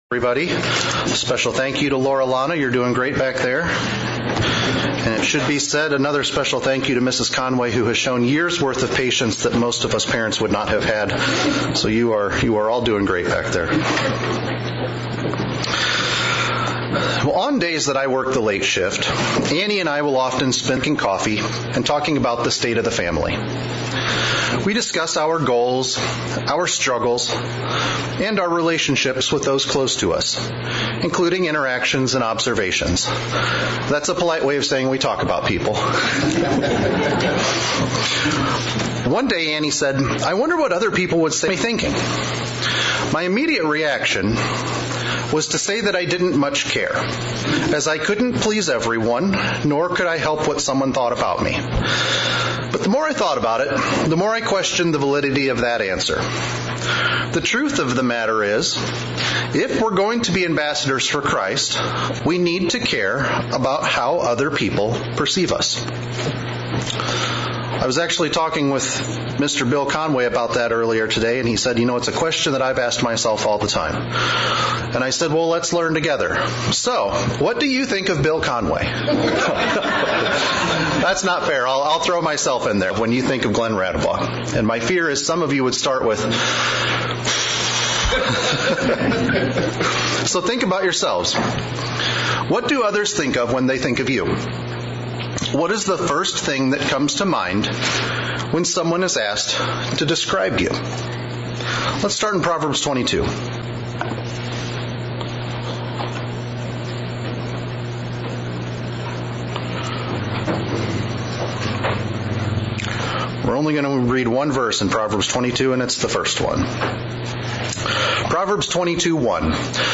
Sermonette looking at the concept of what others think of us. Should we worry about it or be concerned?